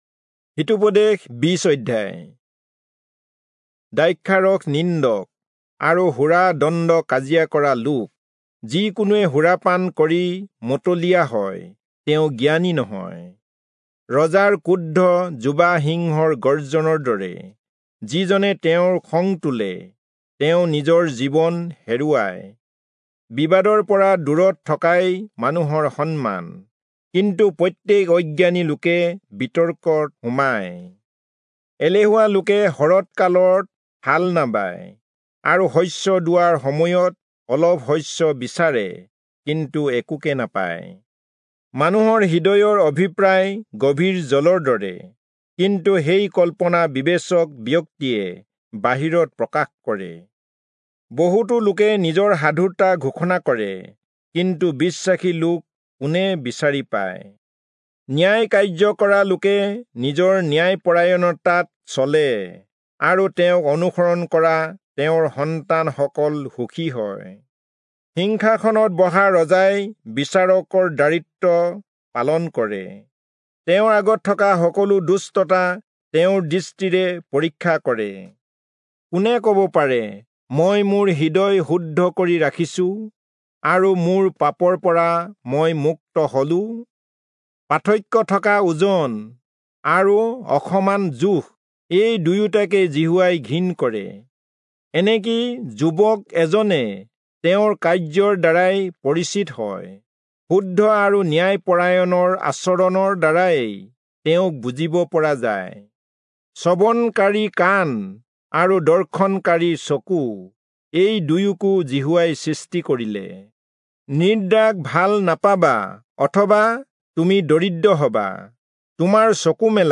Assamese Audio Bible - Proverbs 1 in Web bible version